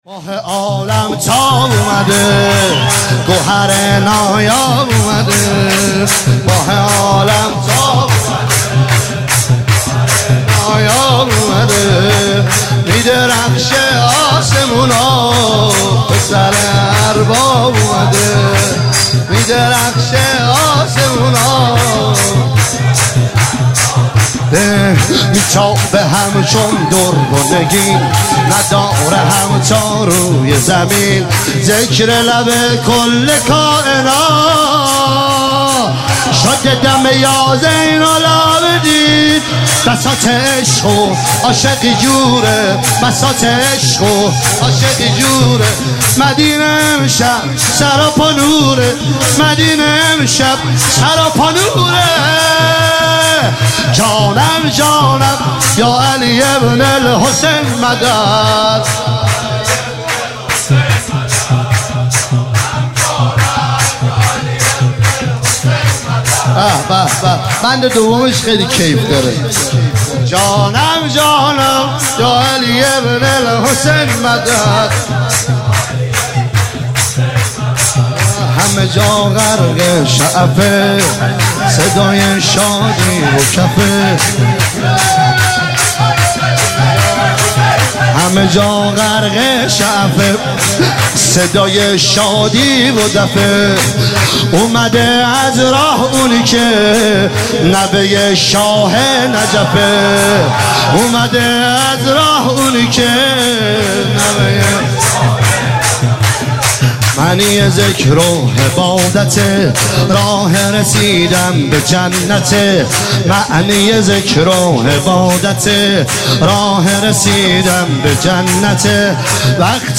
عنوان ولادت سرداران کربلا – شب دوم
سرود-شور